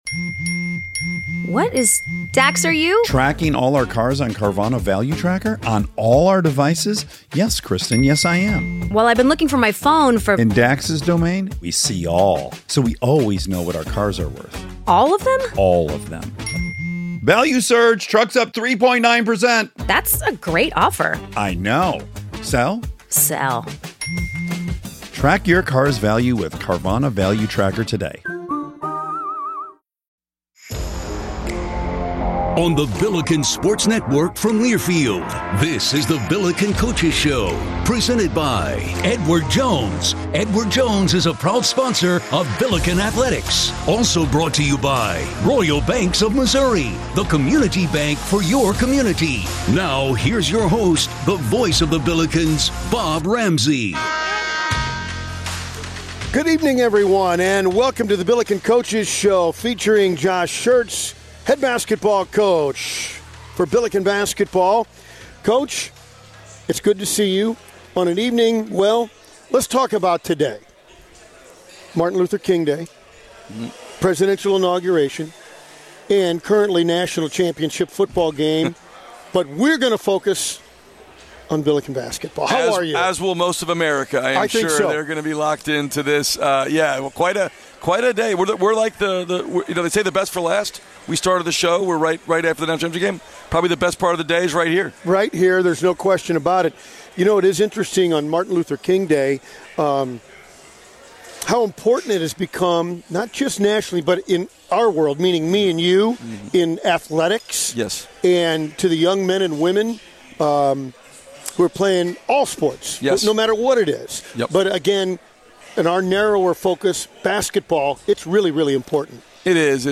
The nightly program has been a fixture on KMOX for many years and features a variety of hosts.